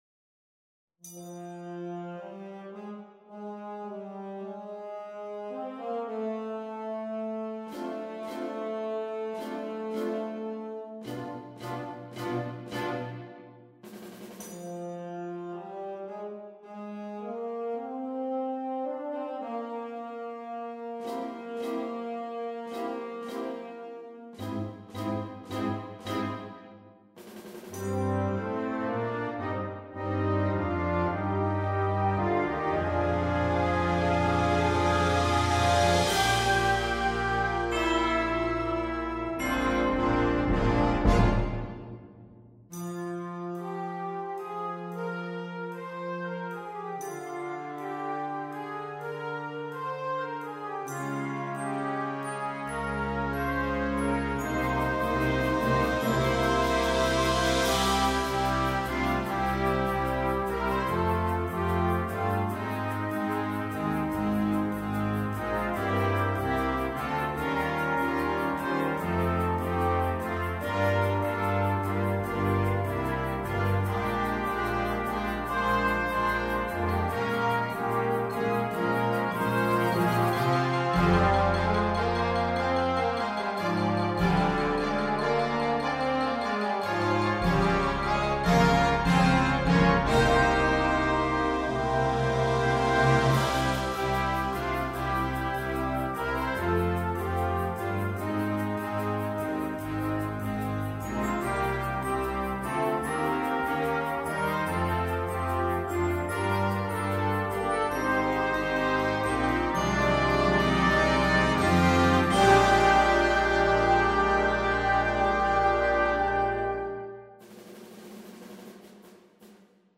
for wind band